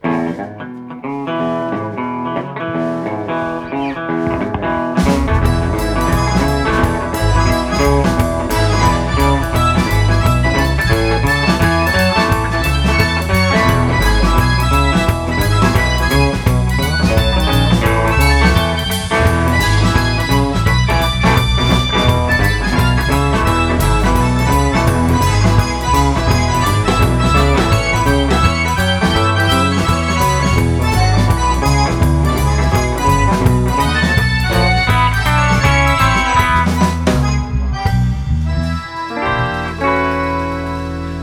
Guitar, Vocals
Guitar, Sax, Clarinet
Vocals, Trombone
Percussion, Guitar, Keys
Keyboards
Bass
Drums